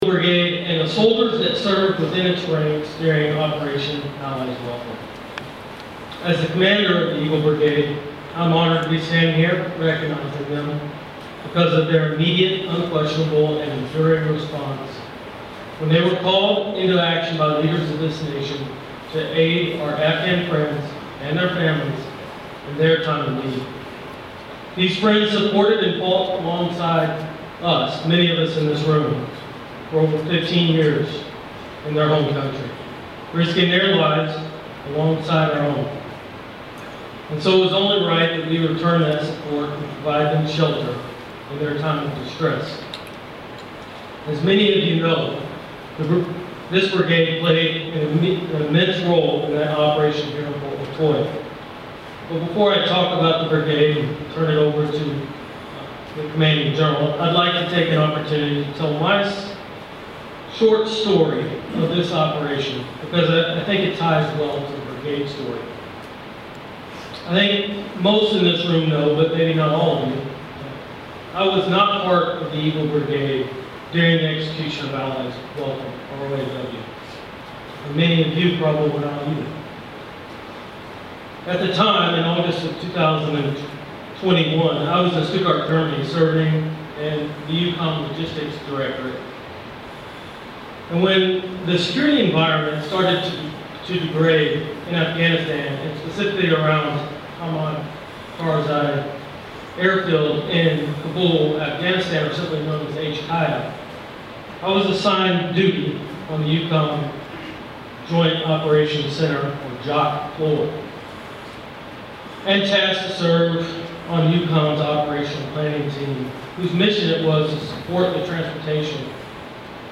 181st Multi-Functional Training Brigade Meritorious Unit Commendation Ceremony, Part II
Soldiers with the 181st Multi-Functional Training Brigade and from units within the brigade participate in an Army Meritorious Unit Citation ceremony Dec. 14, 2023, at Fort McCoy, Wis. During the ceremony, the 181st and its units received the citation for their support for Operation Allies Refuge/Operation Allies Welcome (OAR/OAW) that took place at Fort McCoy from August 2021 to February 2022. The citation was officially presented to the unit by Brig. Gen. William Ryan, First Army Division West commanding general.